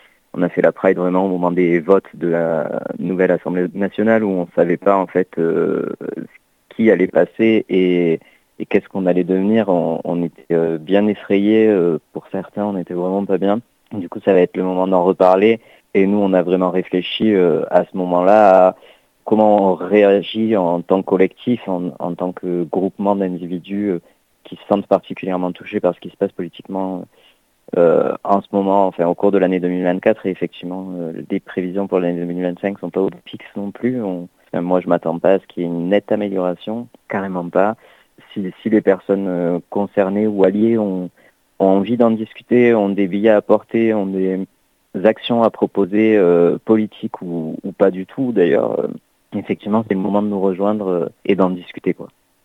bénévole pour Tapage.